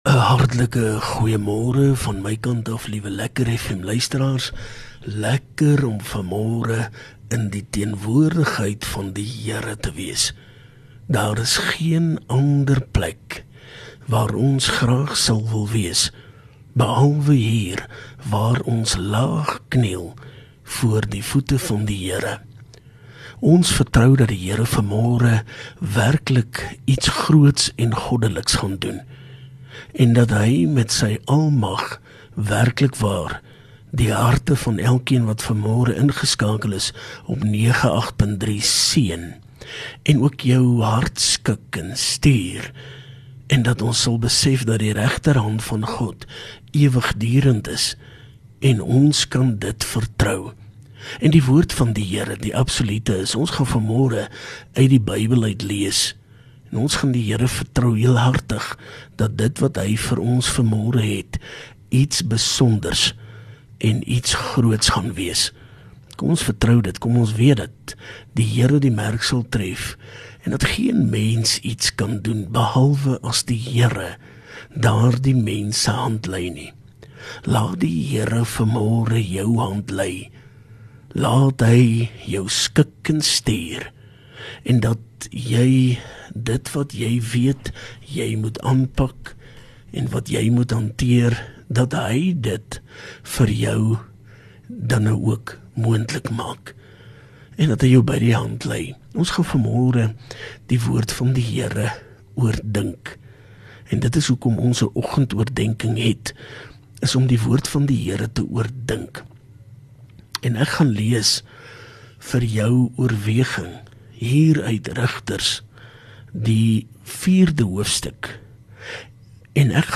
LEKKER FM | Oggendoordenkings